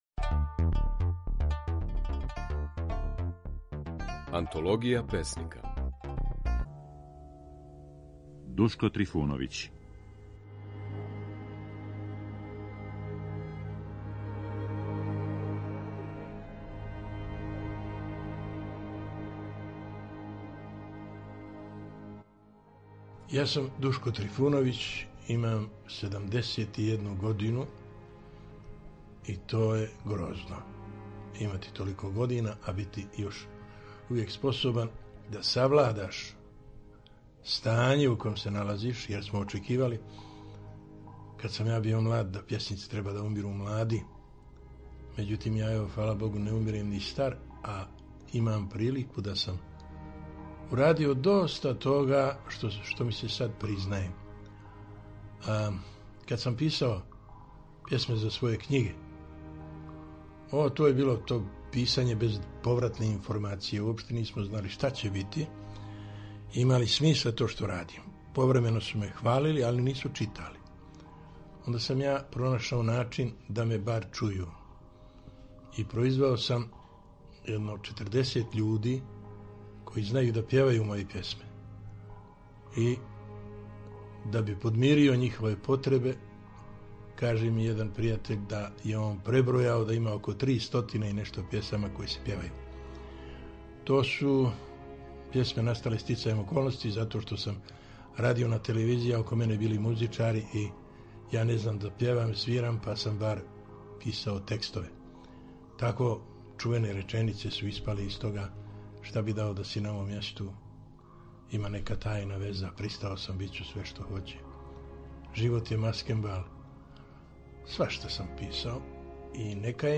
У емисији Aнтологија песника, слушаћемо како је своје стихове говорио песник Душко Трифуновић (1933–2006).